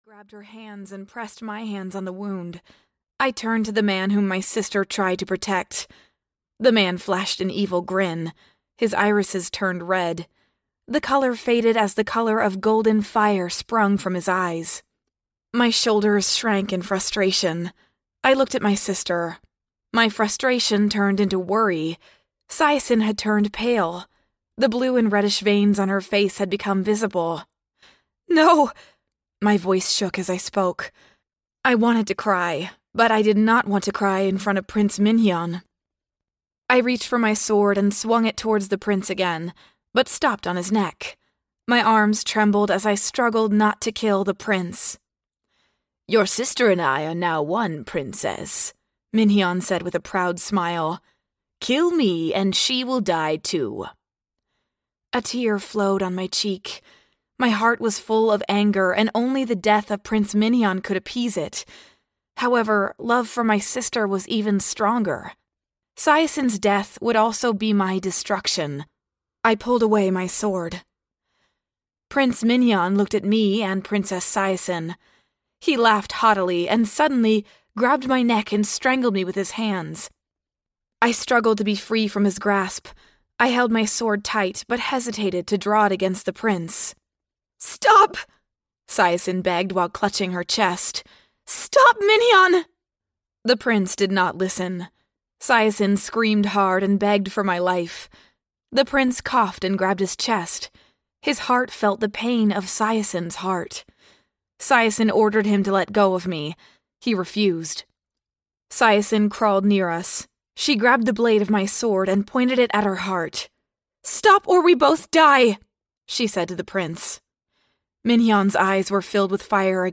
Dragon Harem (EN) audiokniha
Ukázka z knihy